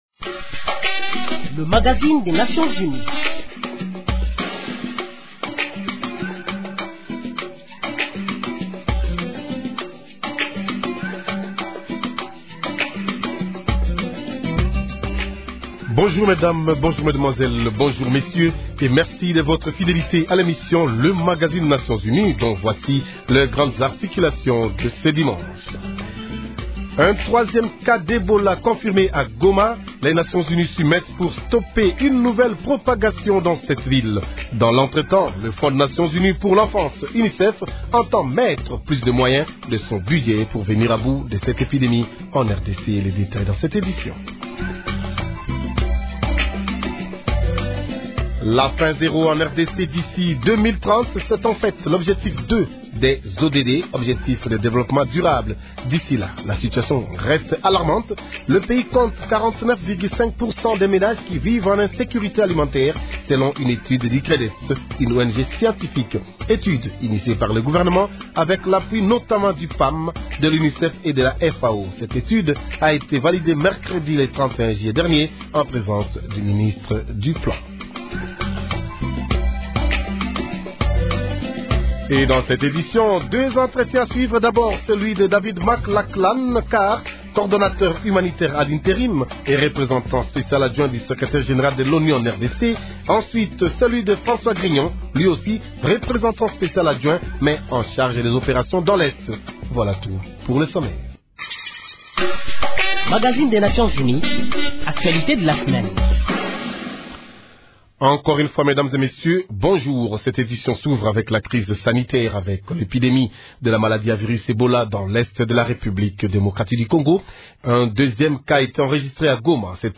Suivez plutôt leur entretien.